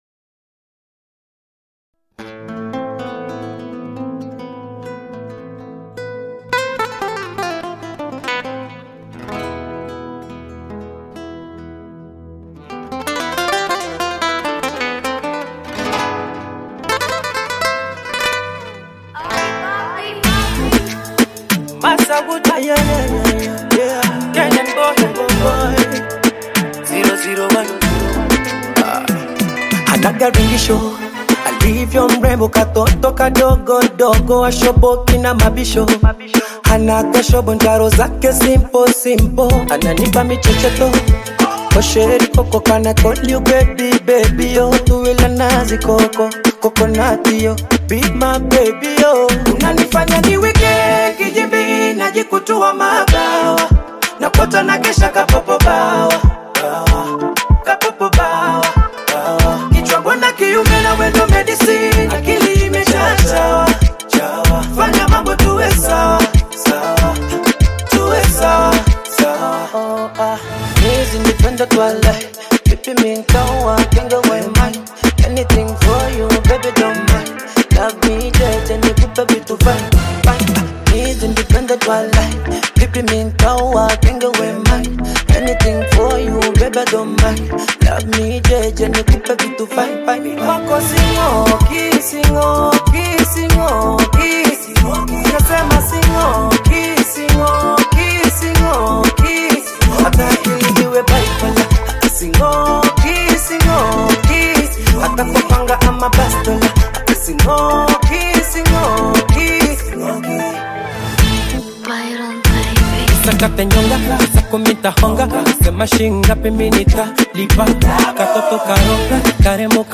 Kenyan Afro pop
upbeat melodic storytelling